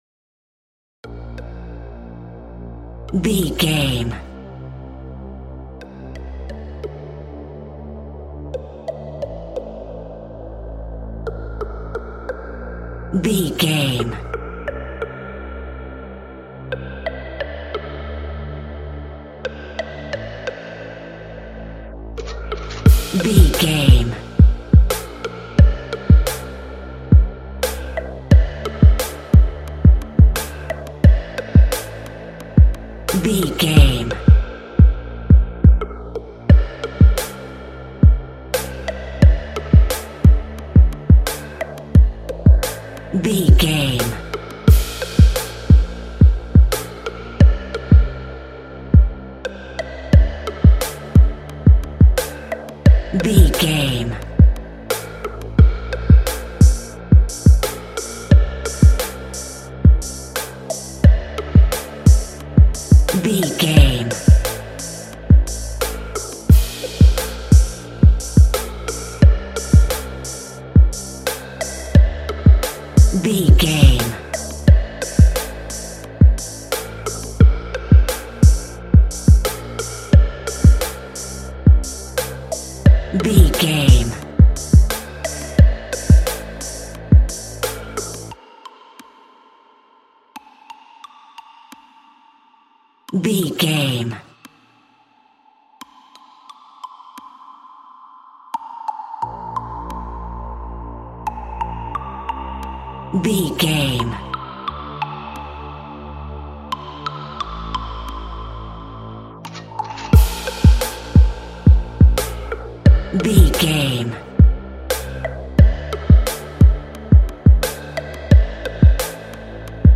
Rappers Backing Beat.
Aeolian/Minor
D
hip hop
chilled
laid back
hip hop drums
hip hop synths
piano
hip hop pads